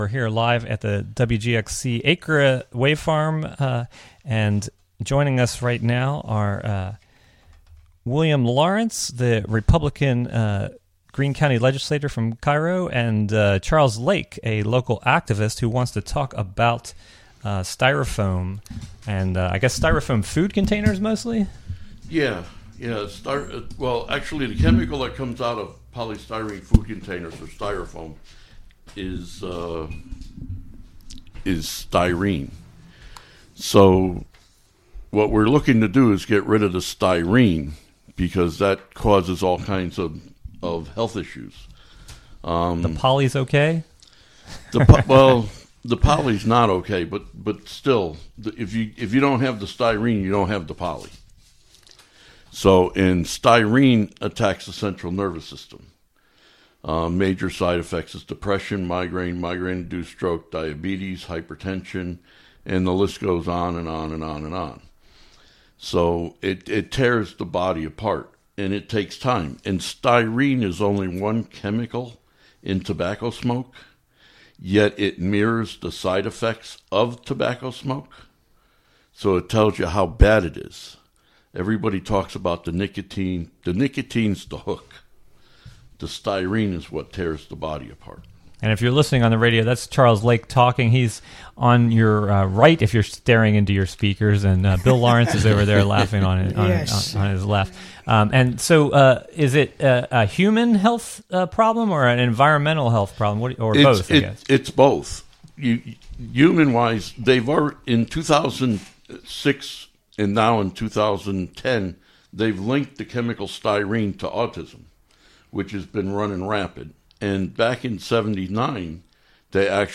11am In the first hour, a conversation with local activ...